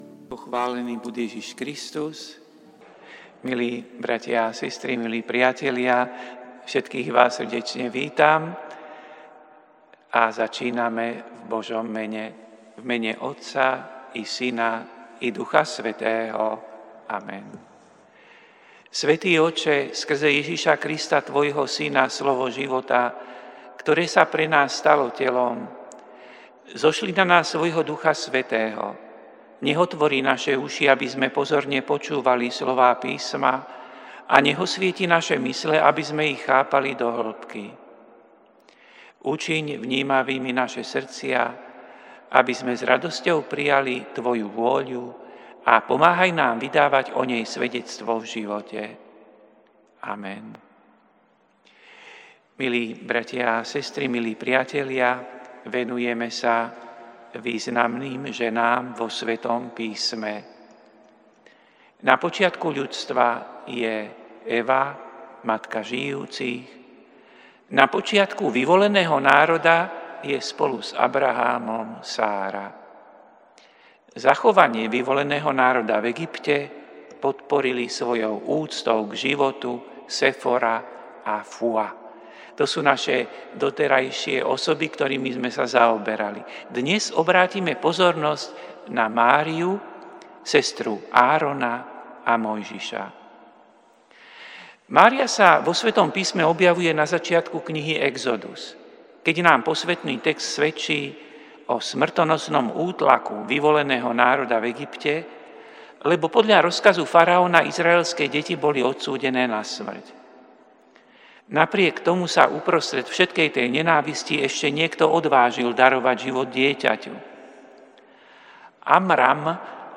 Prinášame plný text a audio záznam z Lectio divina, ktoré odznelo v Katedrále sv. Martina 10. decembra 2025.